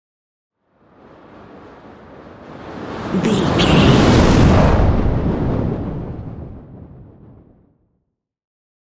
Whoosh fire large
Sound Effects
DOES THIS CLIP CONTAINS LYRICS OR HUMAN VOICE?
No
dark
intense
whoosh